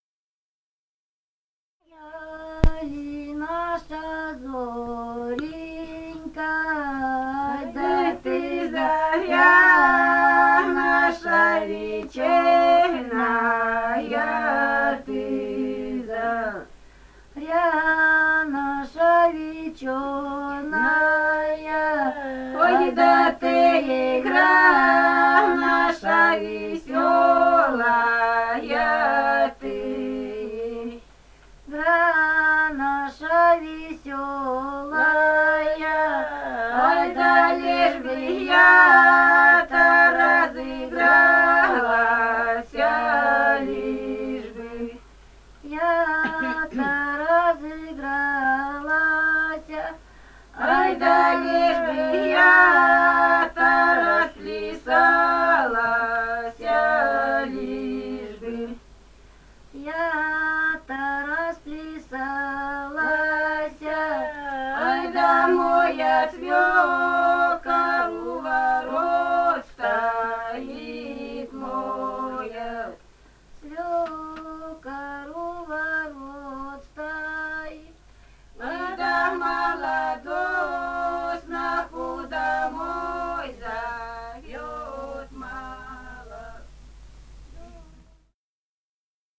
Этномузыкологические исследования и полевые материалы
Алтайский край, с. Тигирек Краснощёковского района, 1967 г. И1002-11б]]